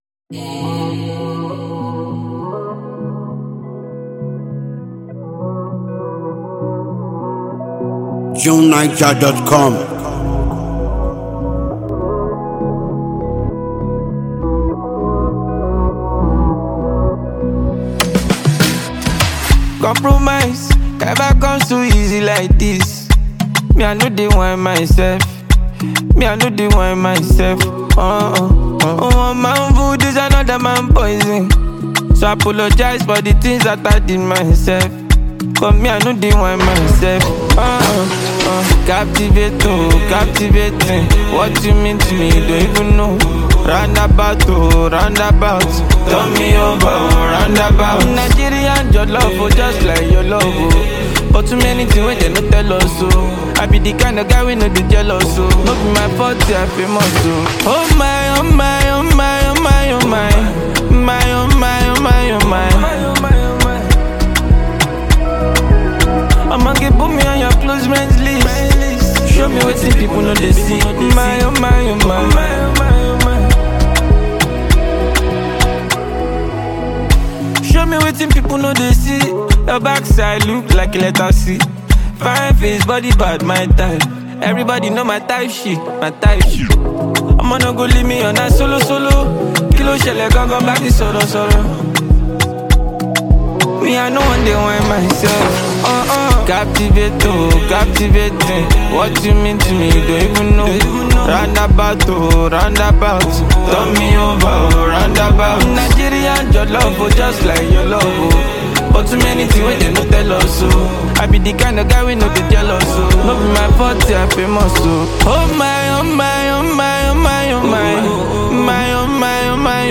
lively groove and captivating mood